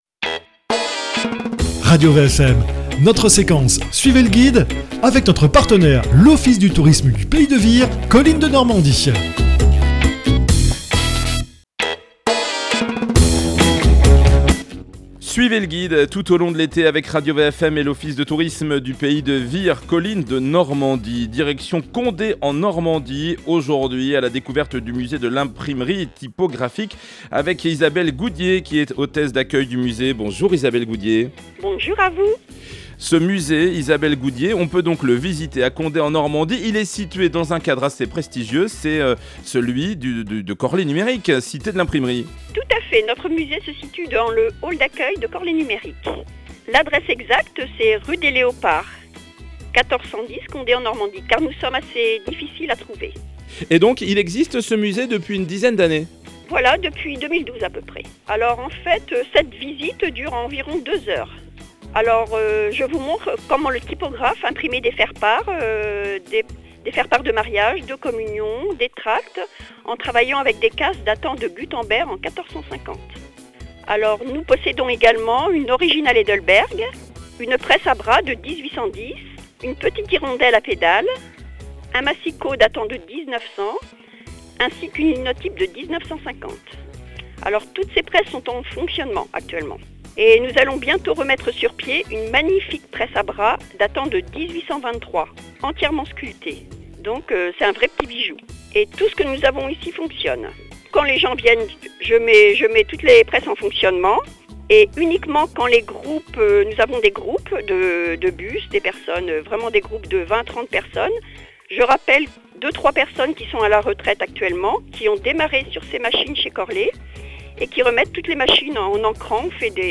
Musée de l’imprimerie typographique à Condé-en-Normandie : une visite guidée sur-mesure pour les auditeurs.